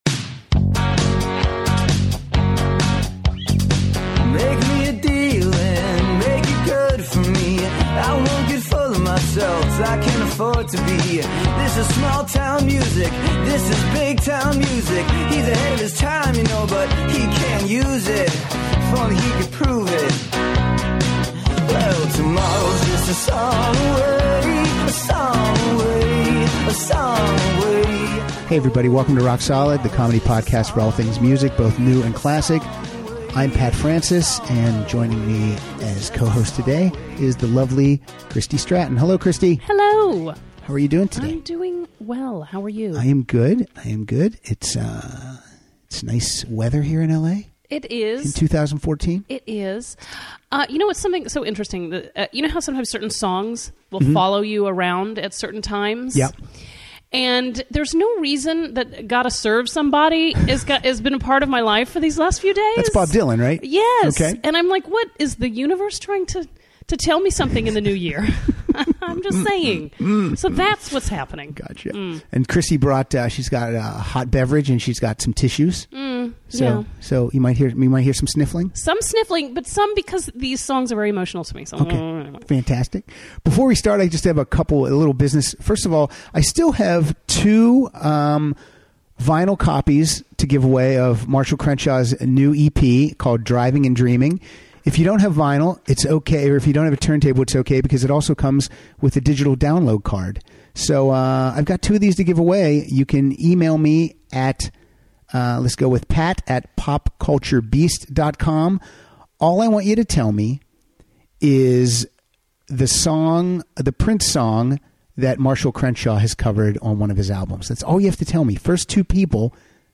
Power Ballads